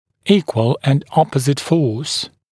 [‘iːkwəl ənd ‘ɔpəzɪt fɔːs][‘и:куэл энд ‘опэзит фо:с]равная и противоположно направленная сила